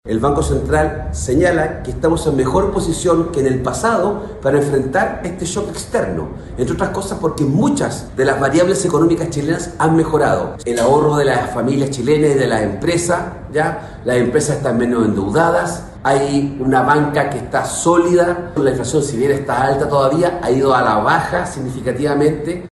El senador del PPD, Ricardo Lagos Weber, valoró este repunte que muestra la economía, señalando que Chile se encuentra en una mejor posición a nivel macroeconómico.